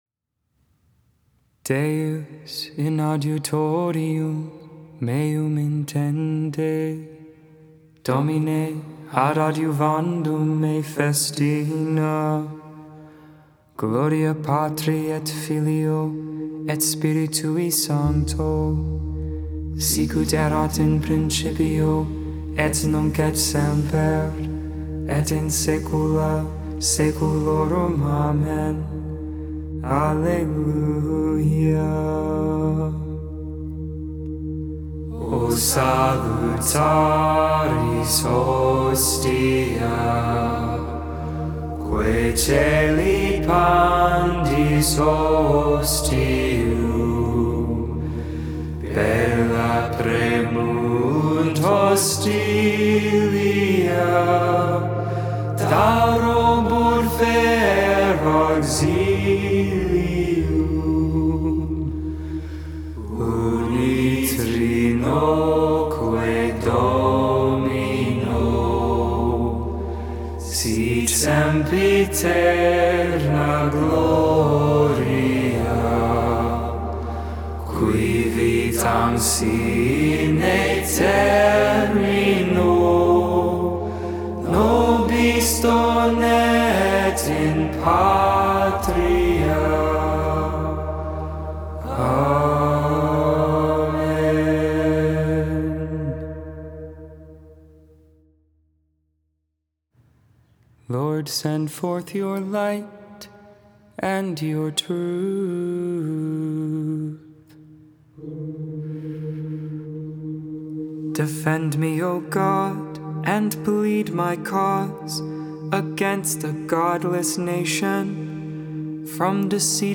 Lauds, Morning Prayer for the 2nd Tuesday in Advent, December 7th, 2021, Memorial of St. Ambrose.
Hymn
Benedictus (English, Tone 8, Luke 1v68-79) Intercessions: Come, Lord Jesus!